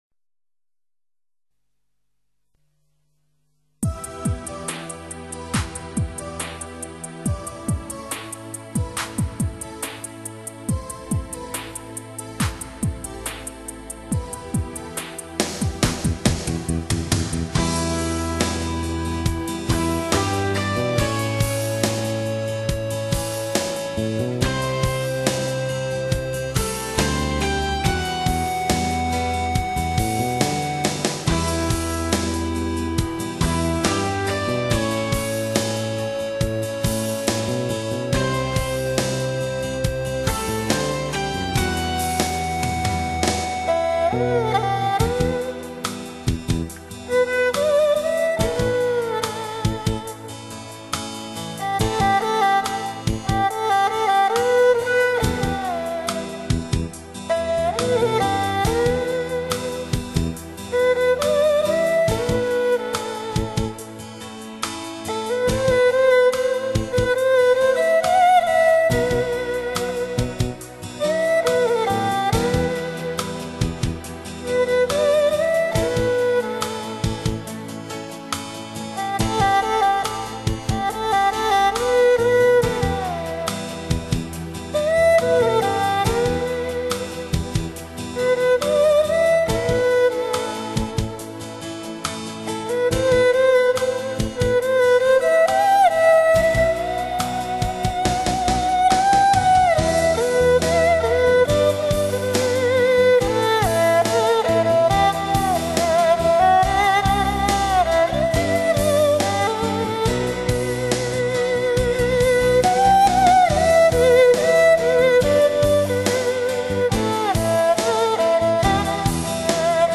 音乐风格: 轻音乐
这套音乐专辑以华语通俗歌曲改编而成。
二胡